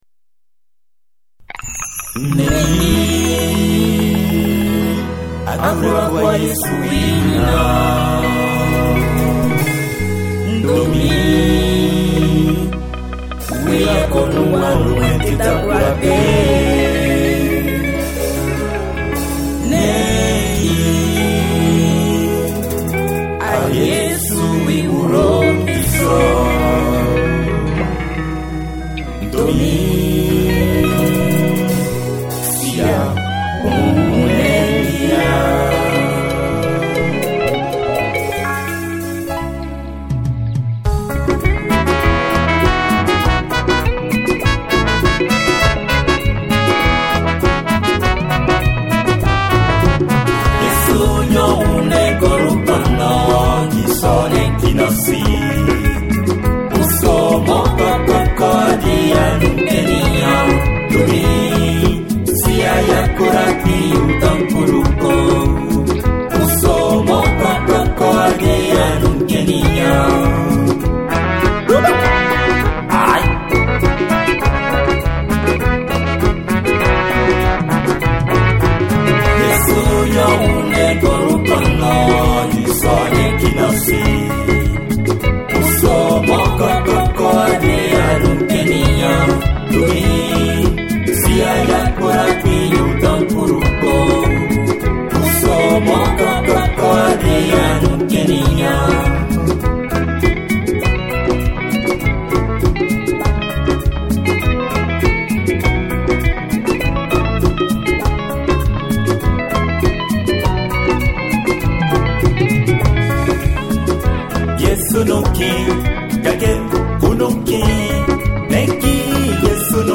4.85Mo - Urban Mp3 - T : 383 fois - E : 3 fois..